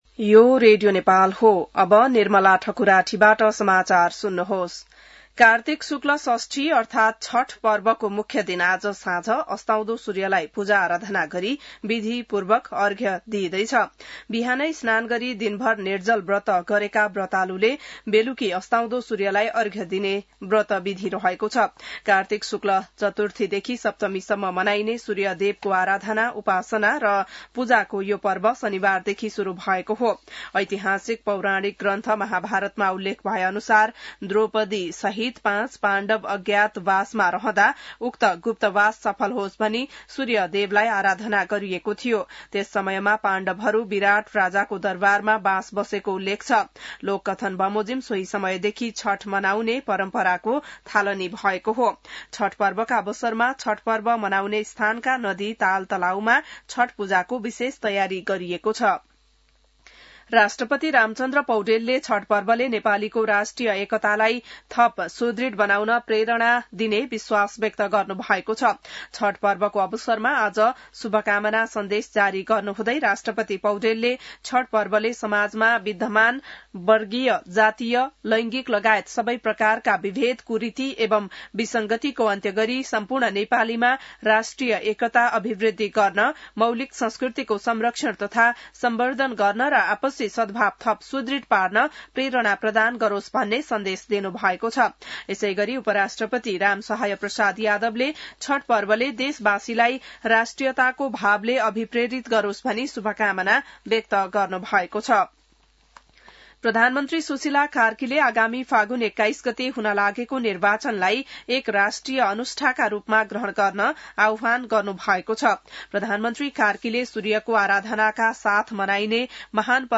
बिहान १० बजेको नेपाली समाचार : १० कार्तिक , २०८२